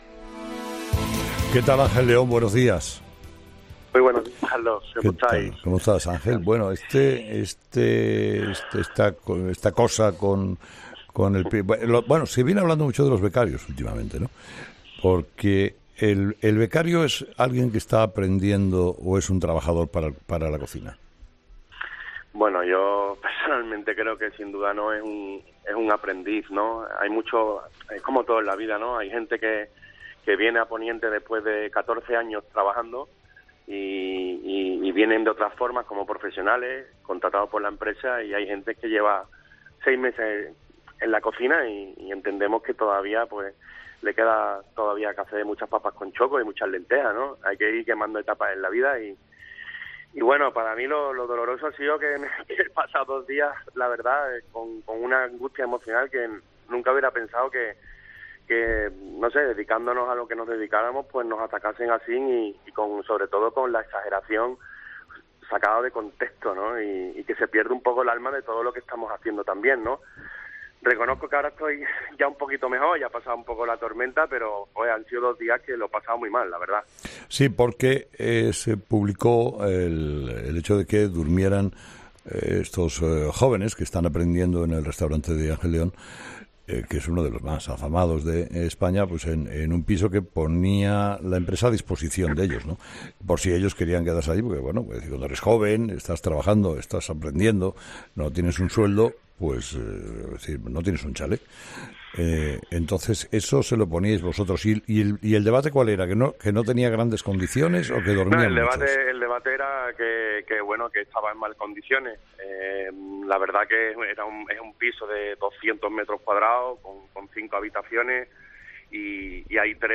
Esucha la entrevista a Ángel León, el 'chef del mar', en 'Herrera en COPE'